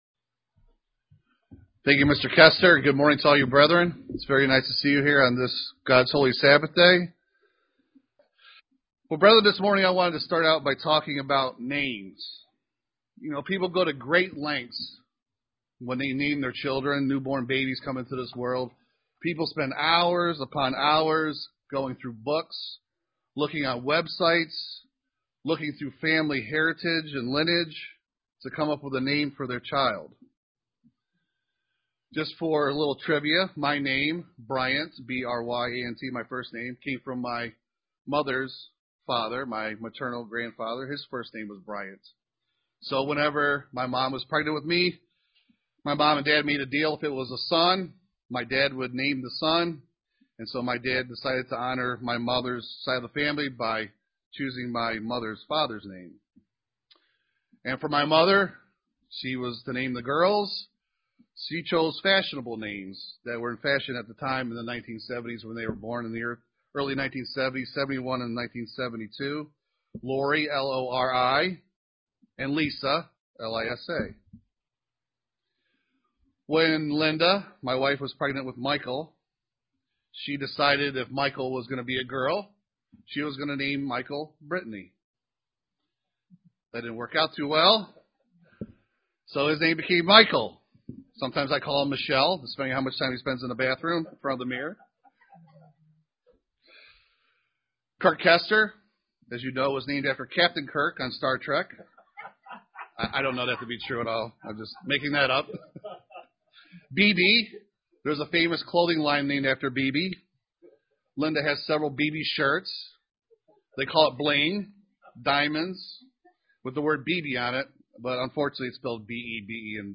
Print What the Bible says about sacred named UCG Sermon Studying the bible?